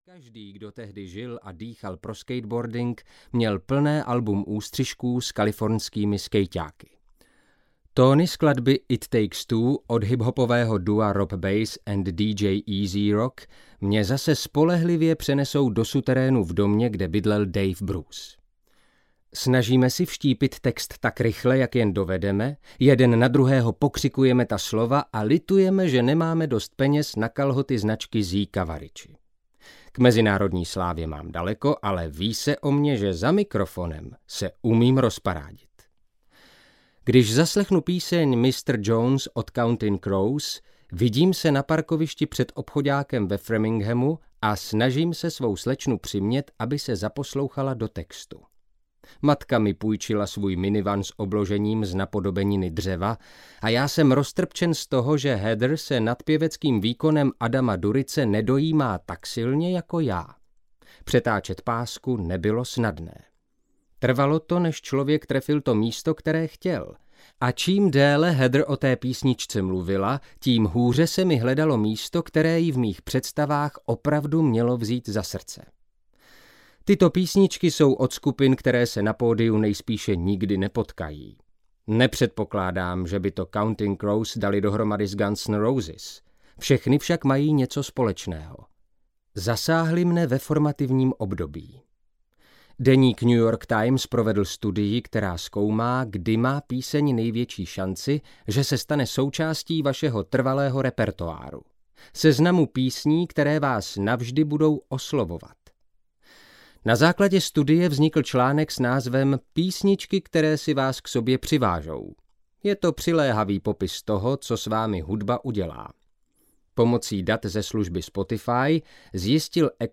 Soundtrack v hlavě audiokniha
Ukázka z knihy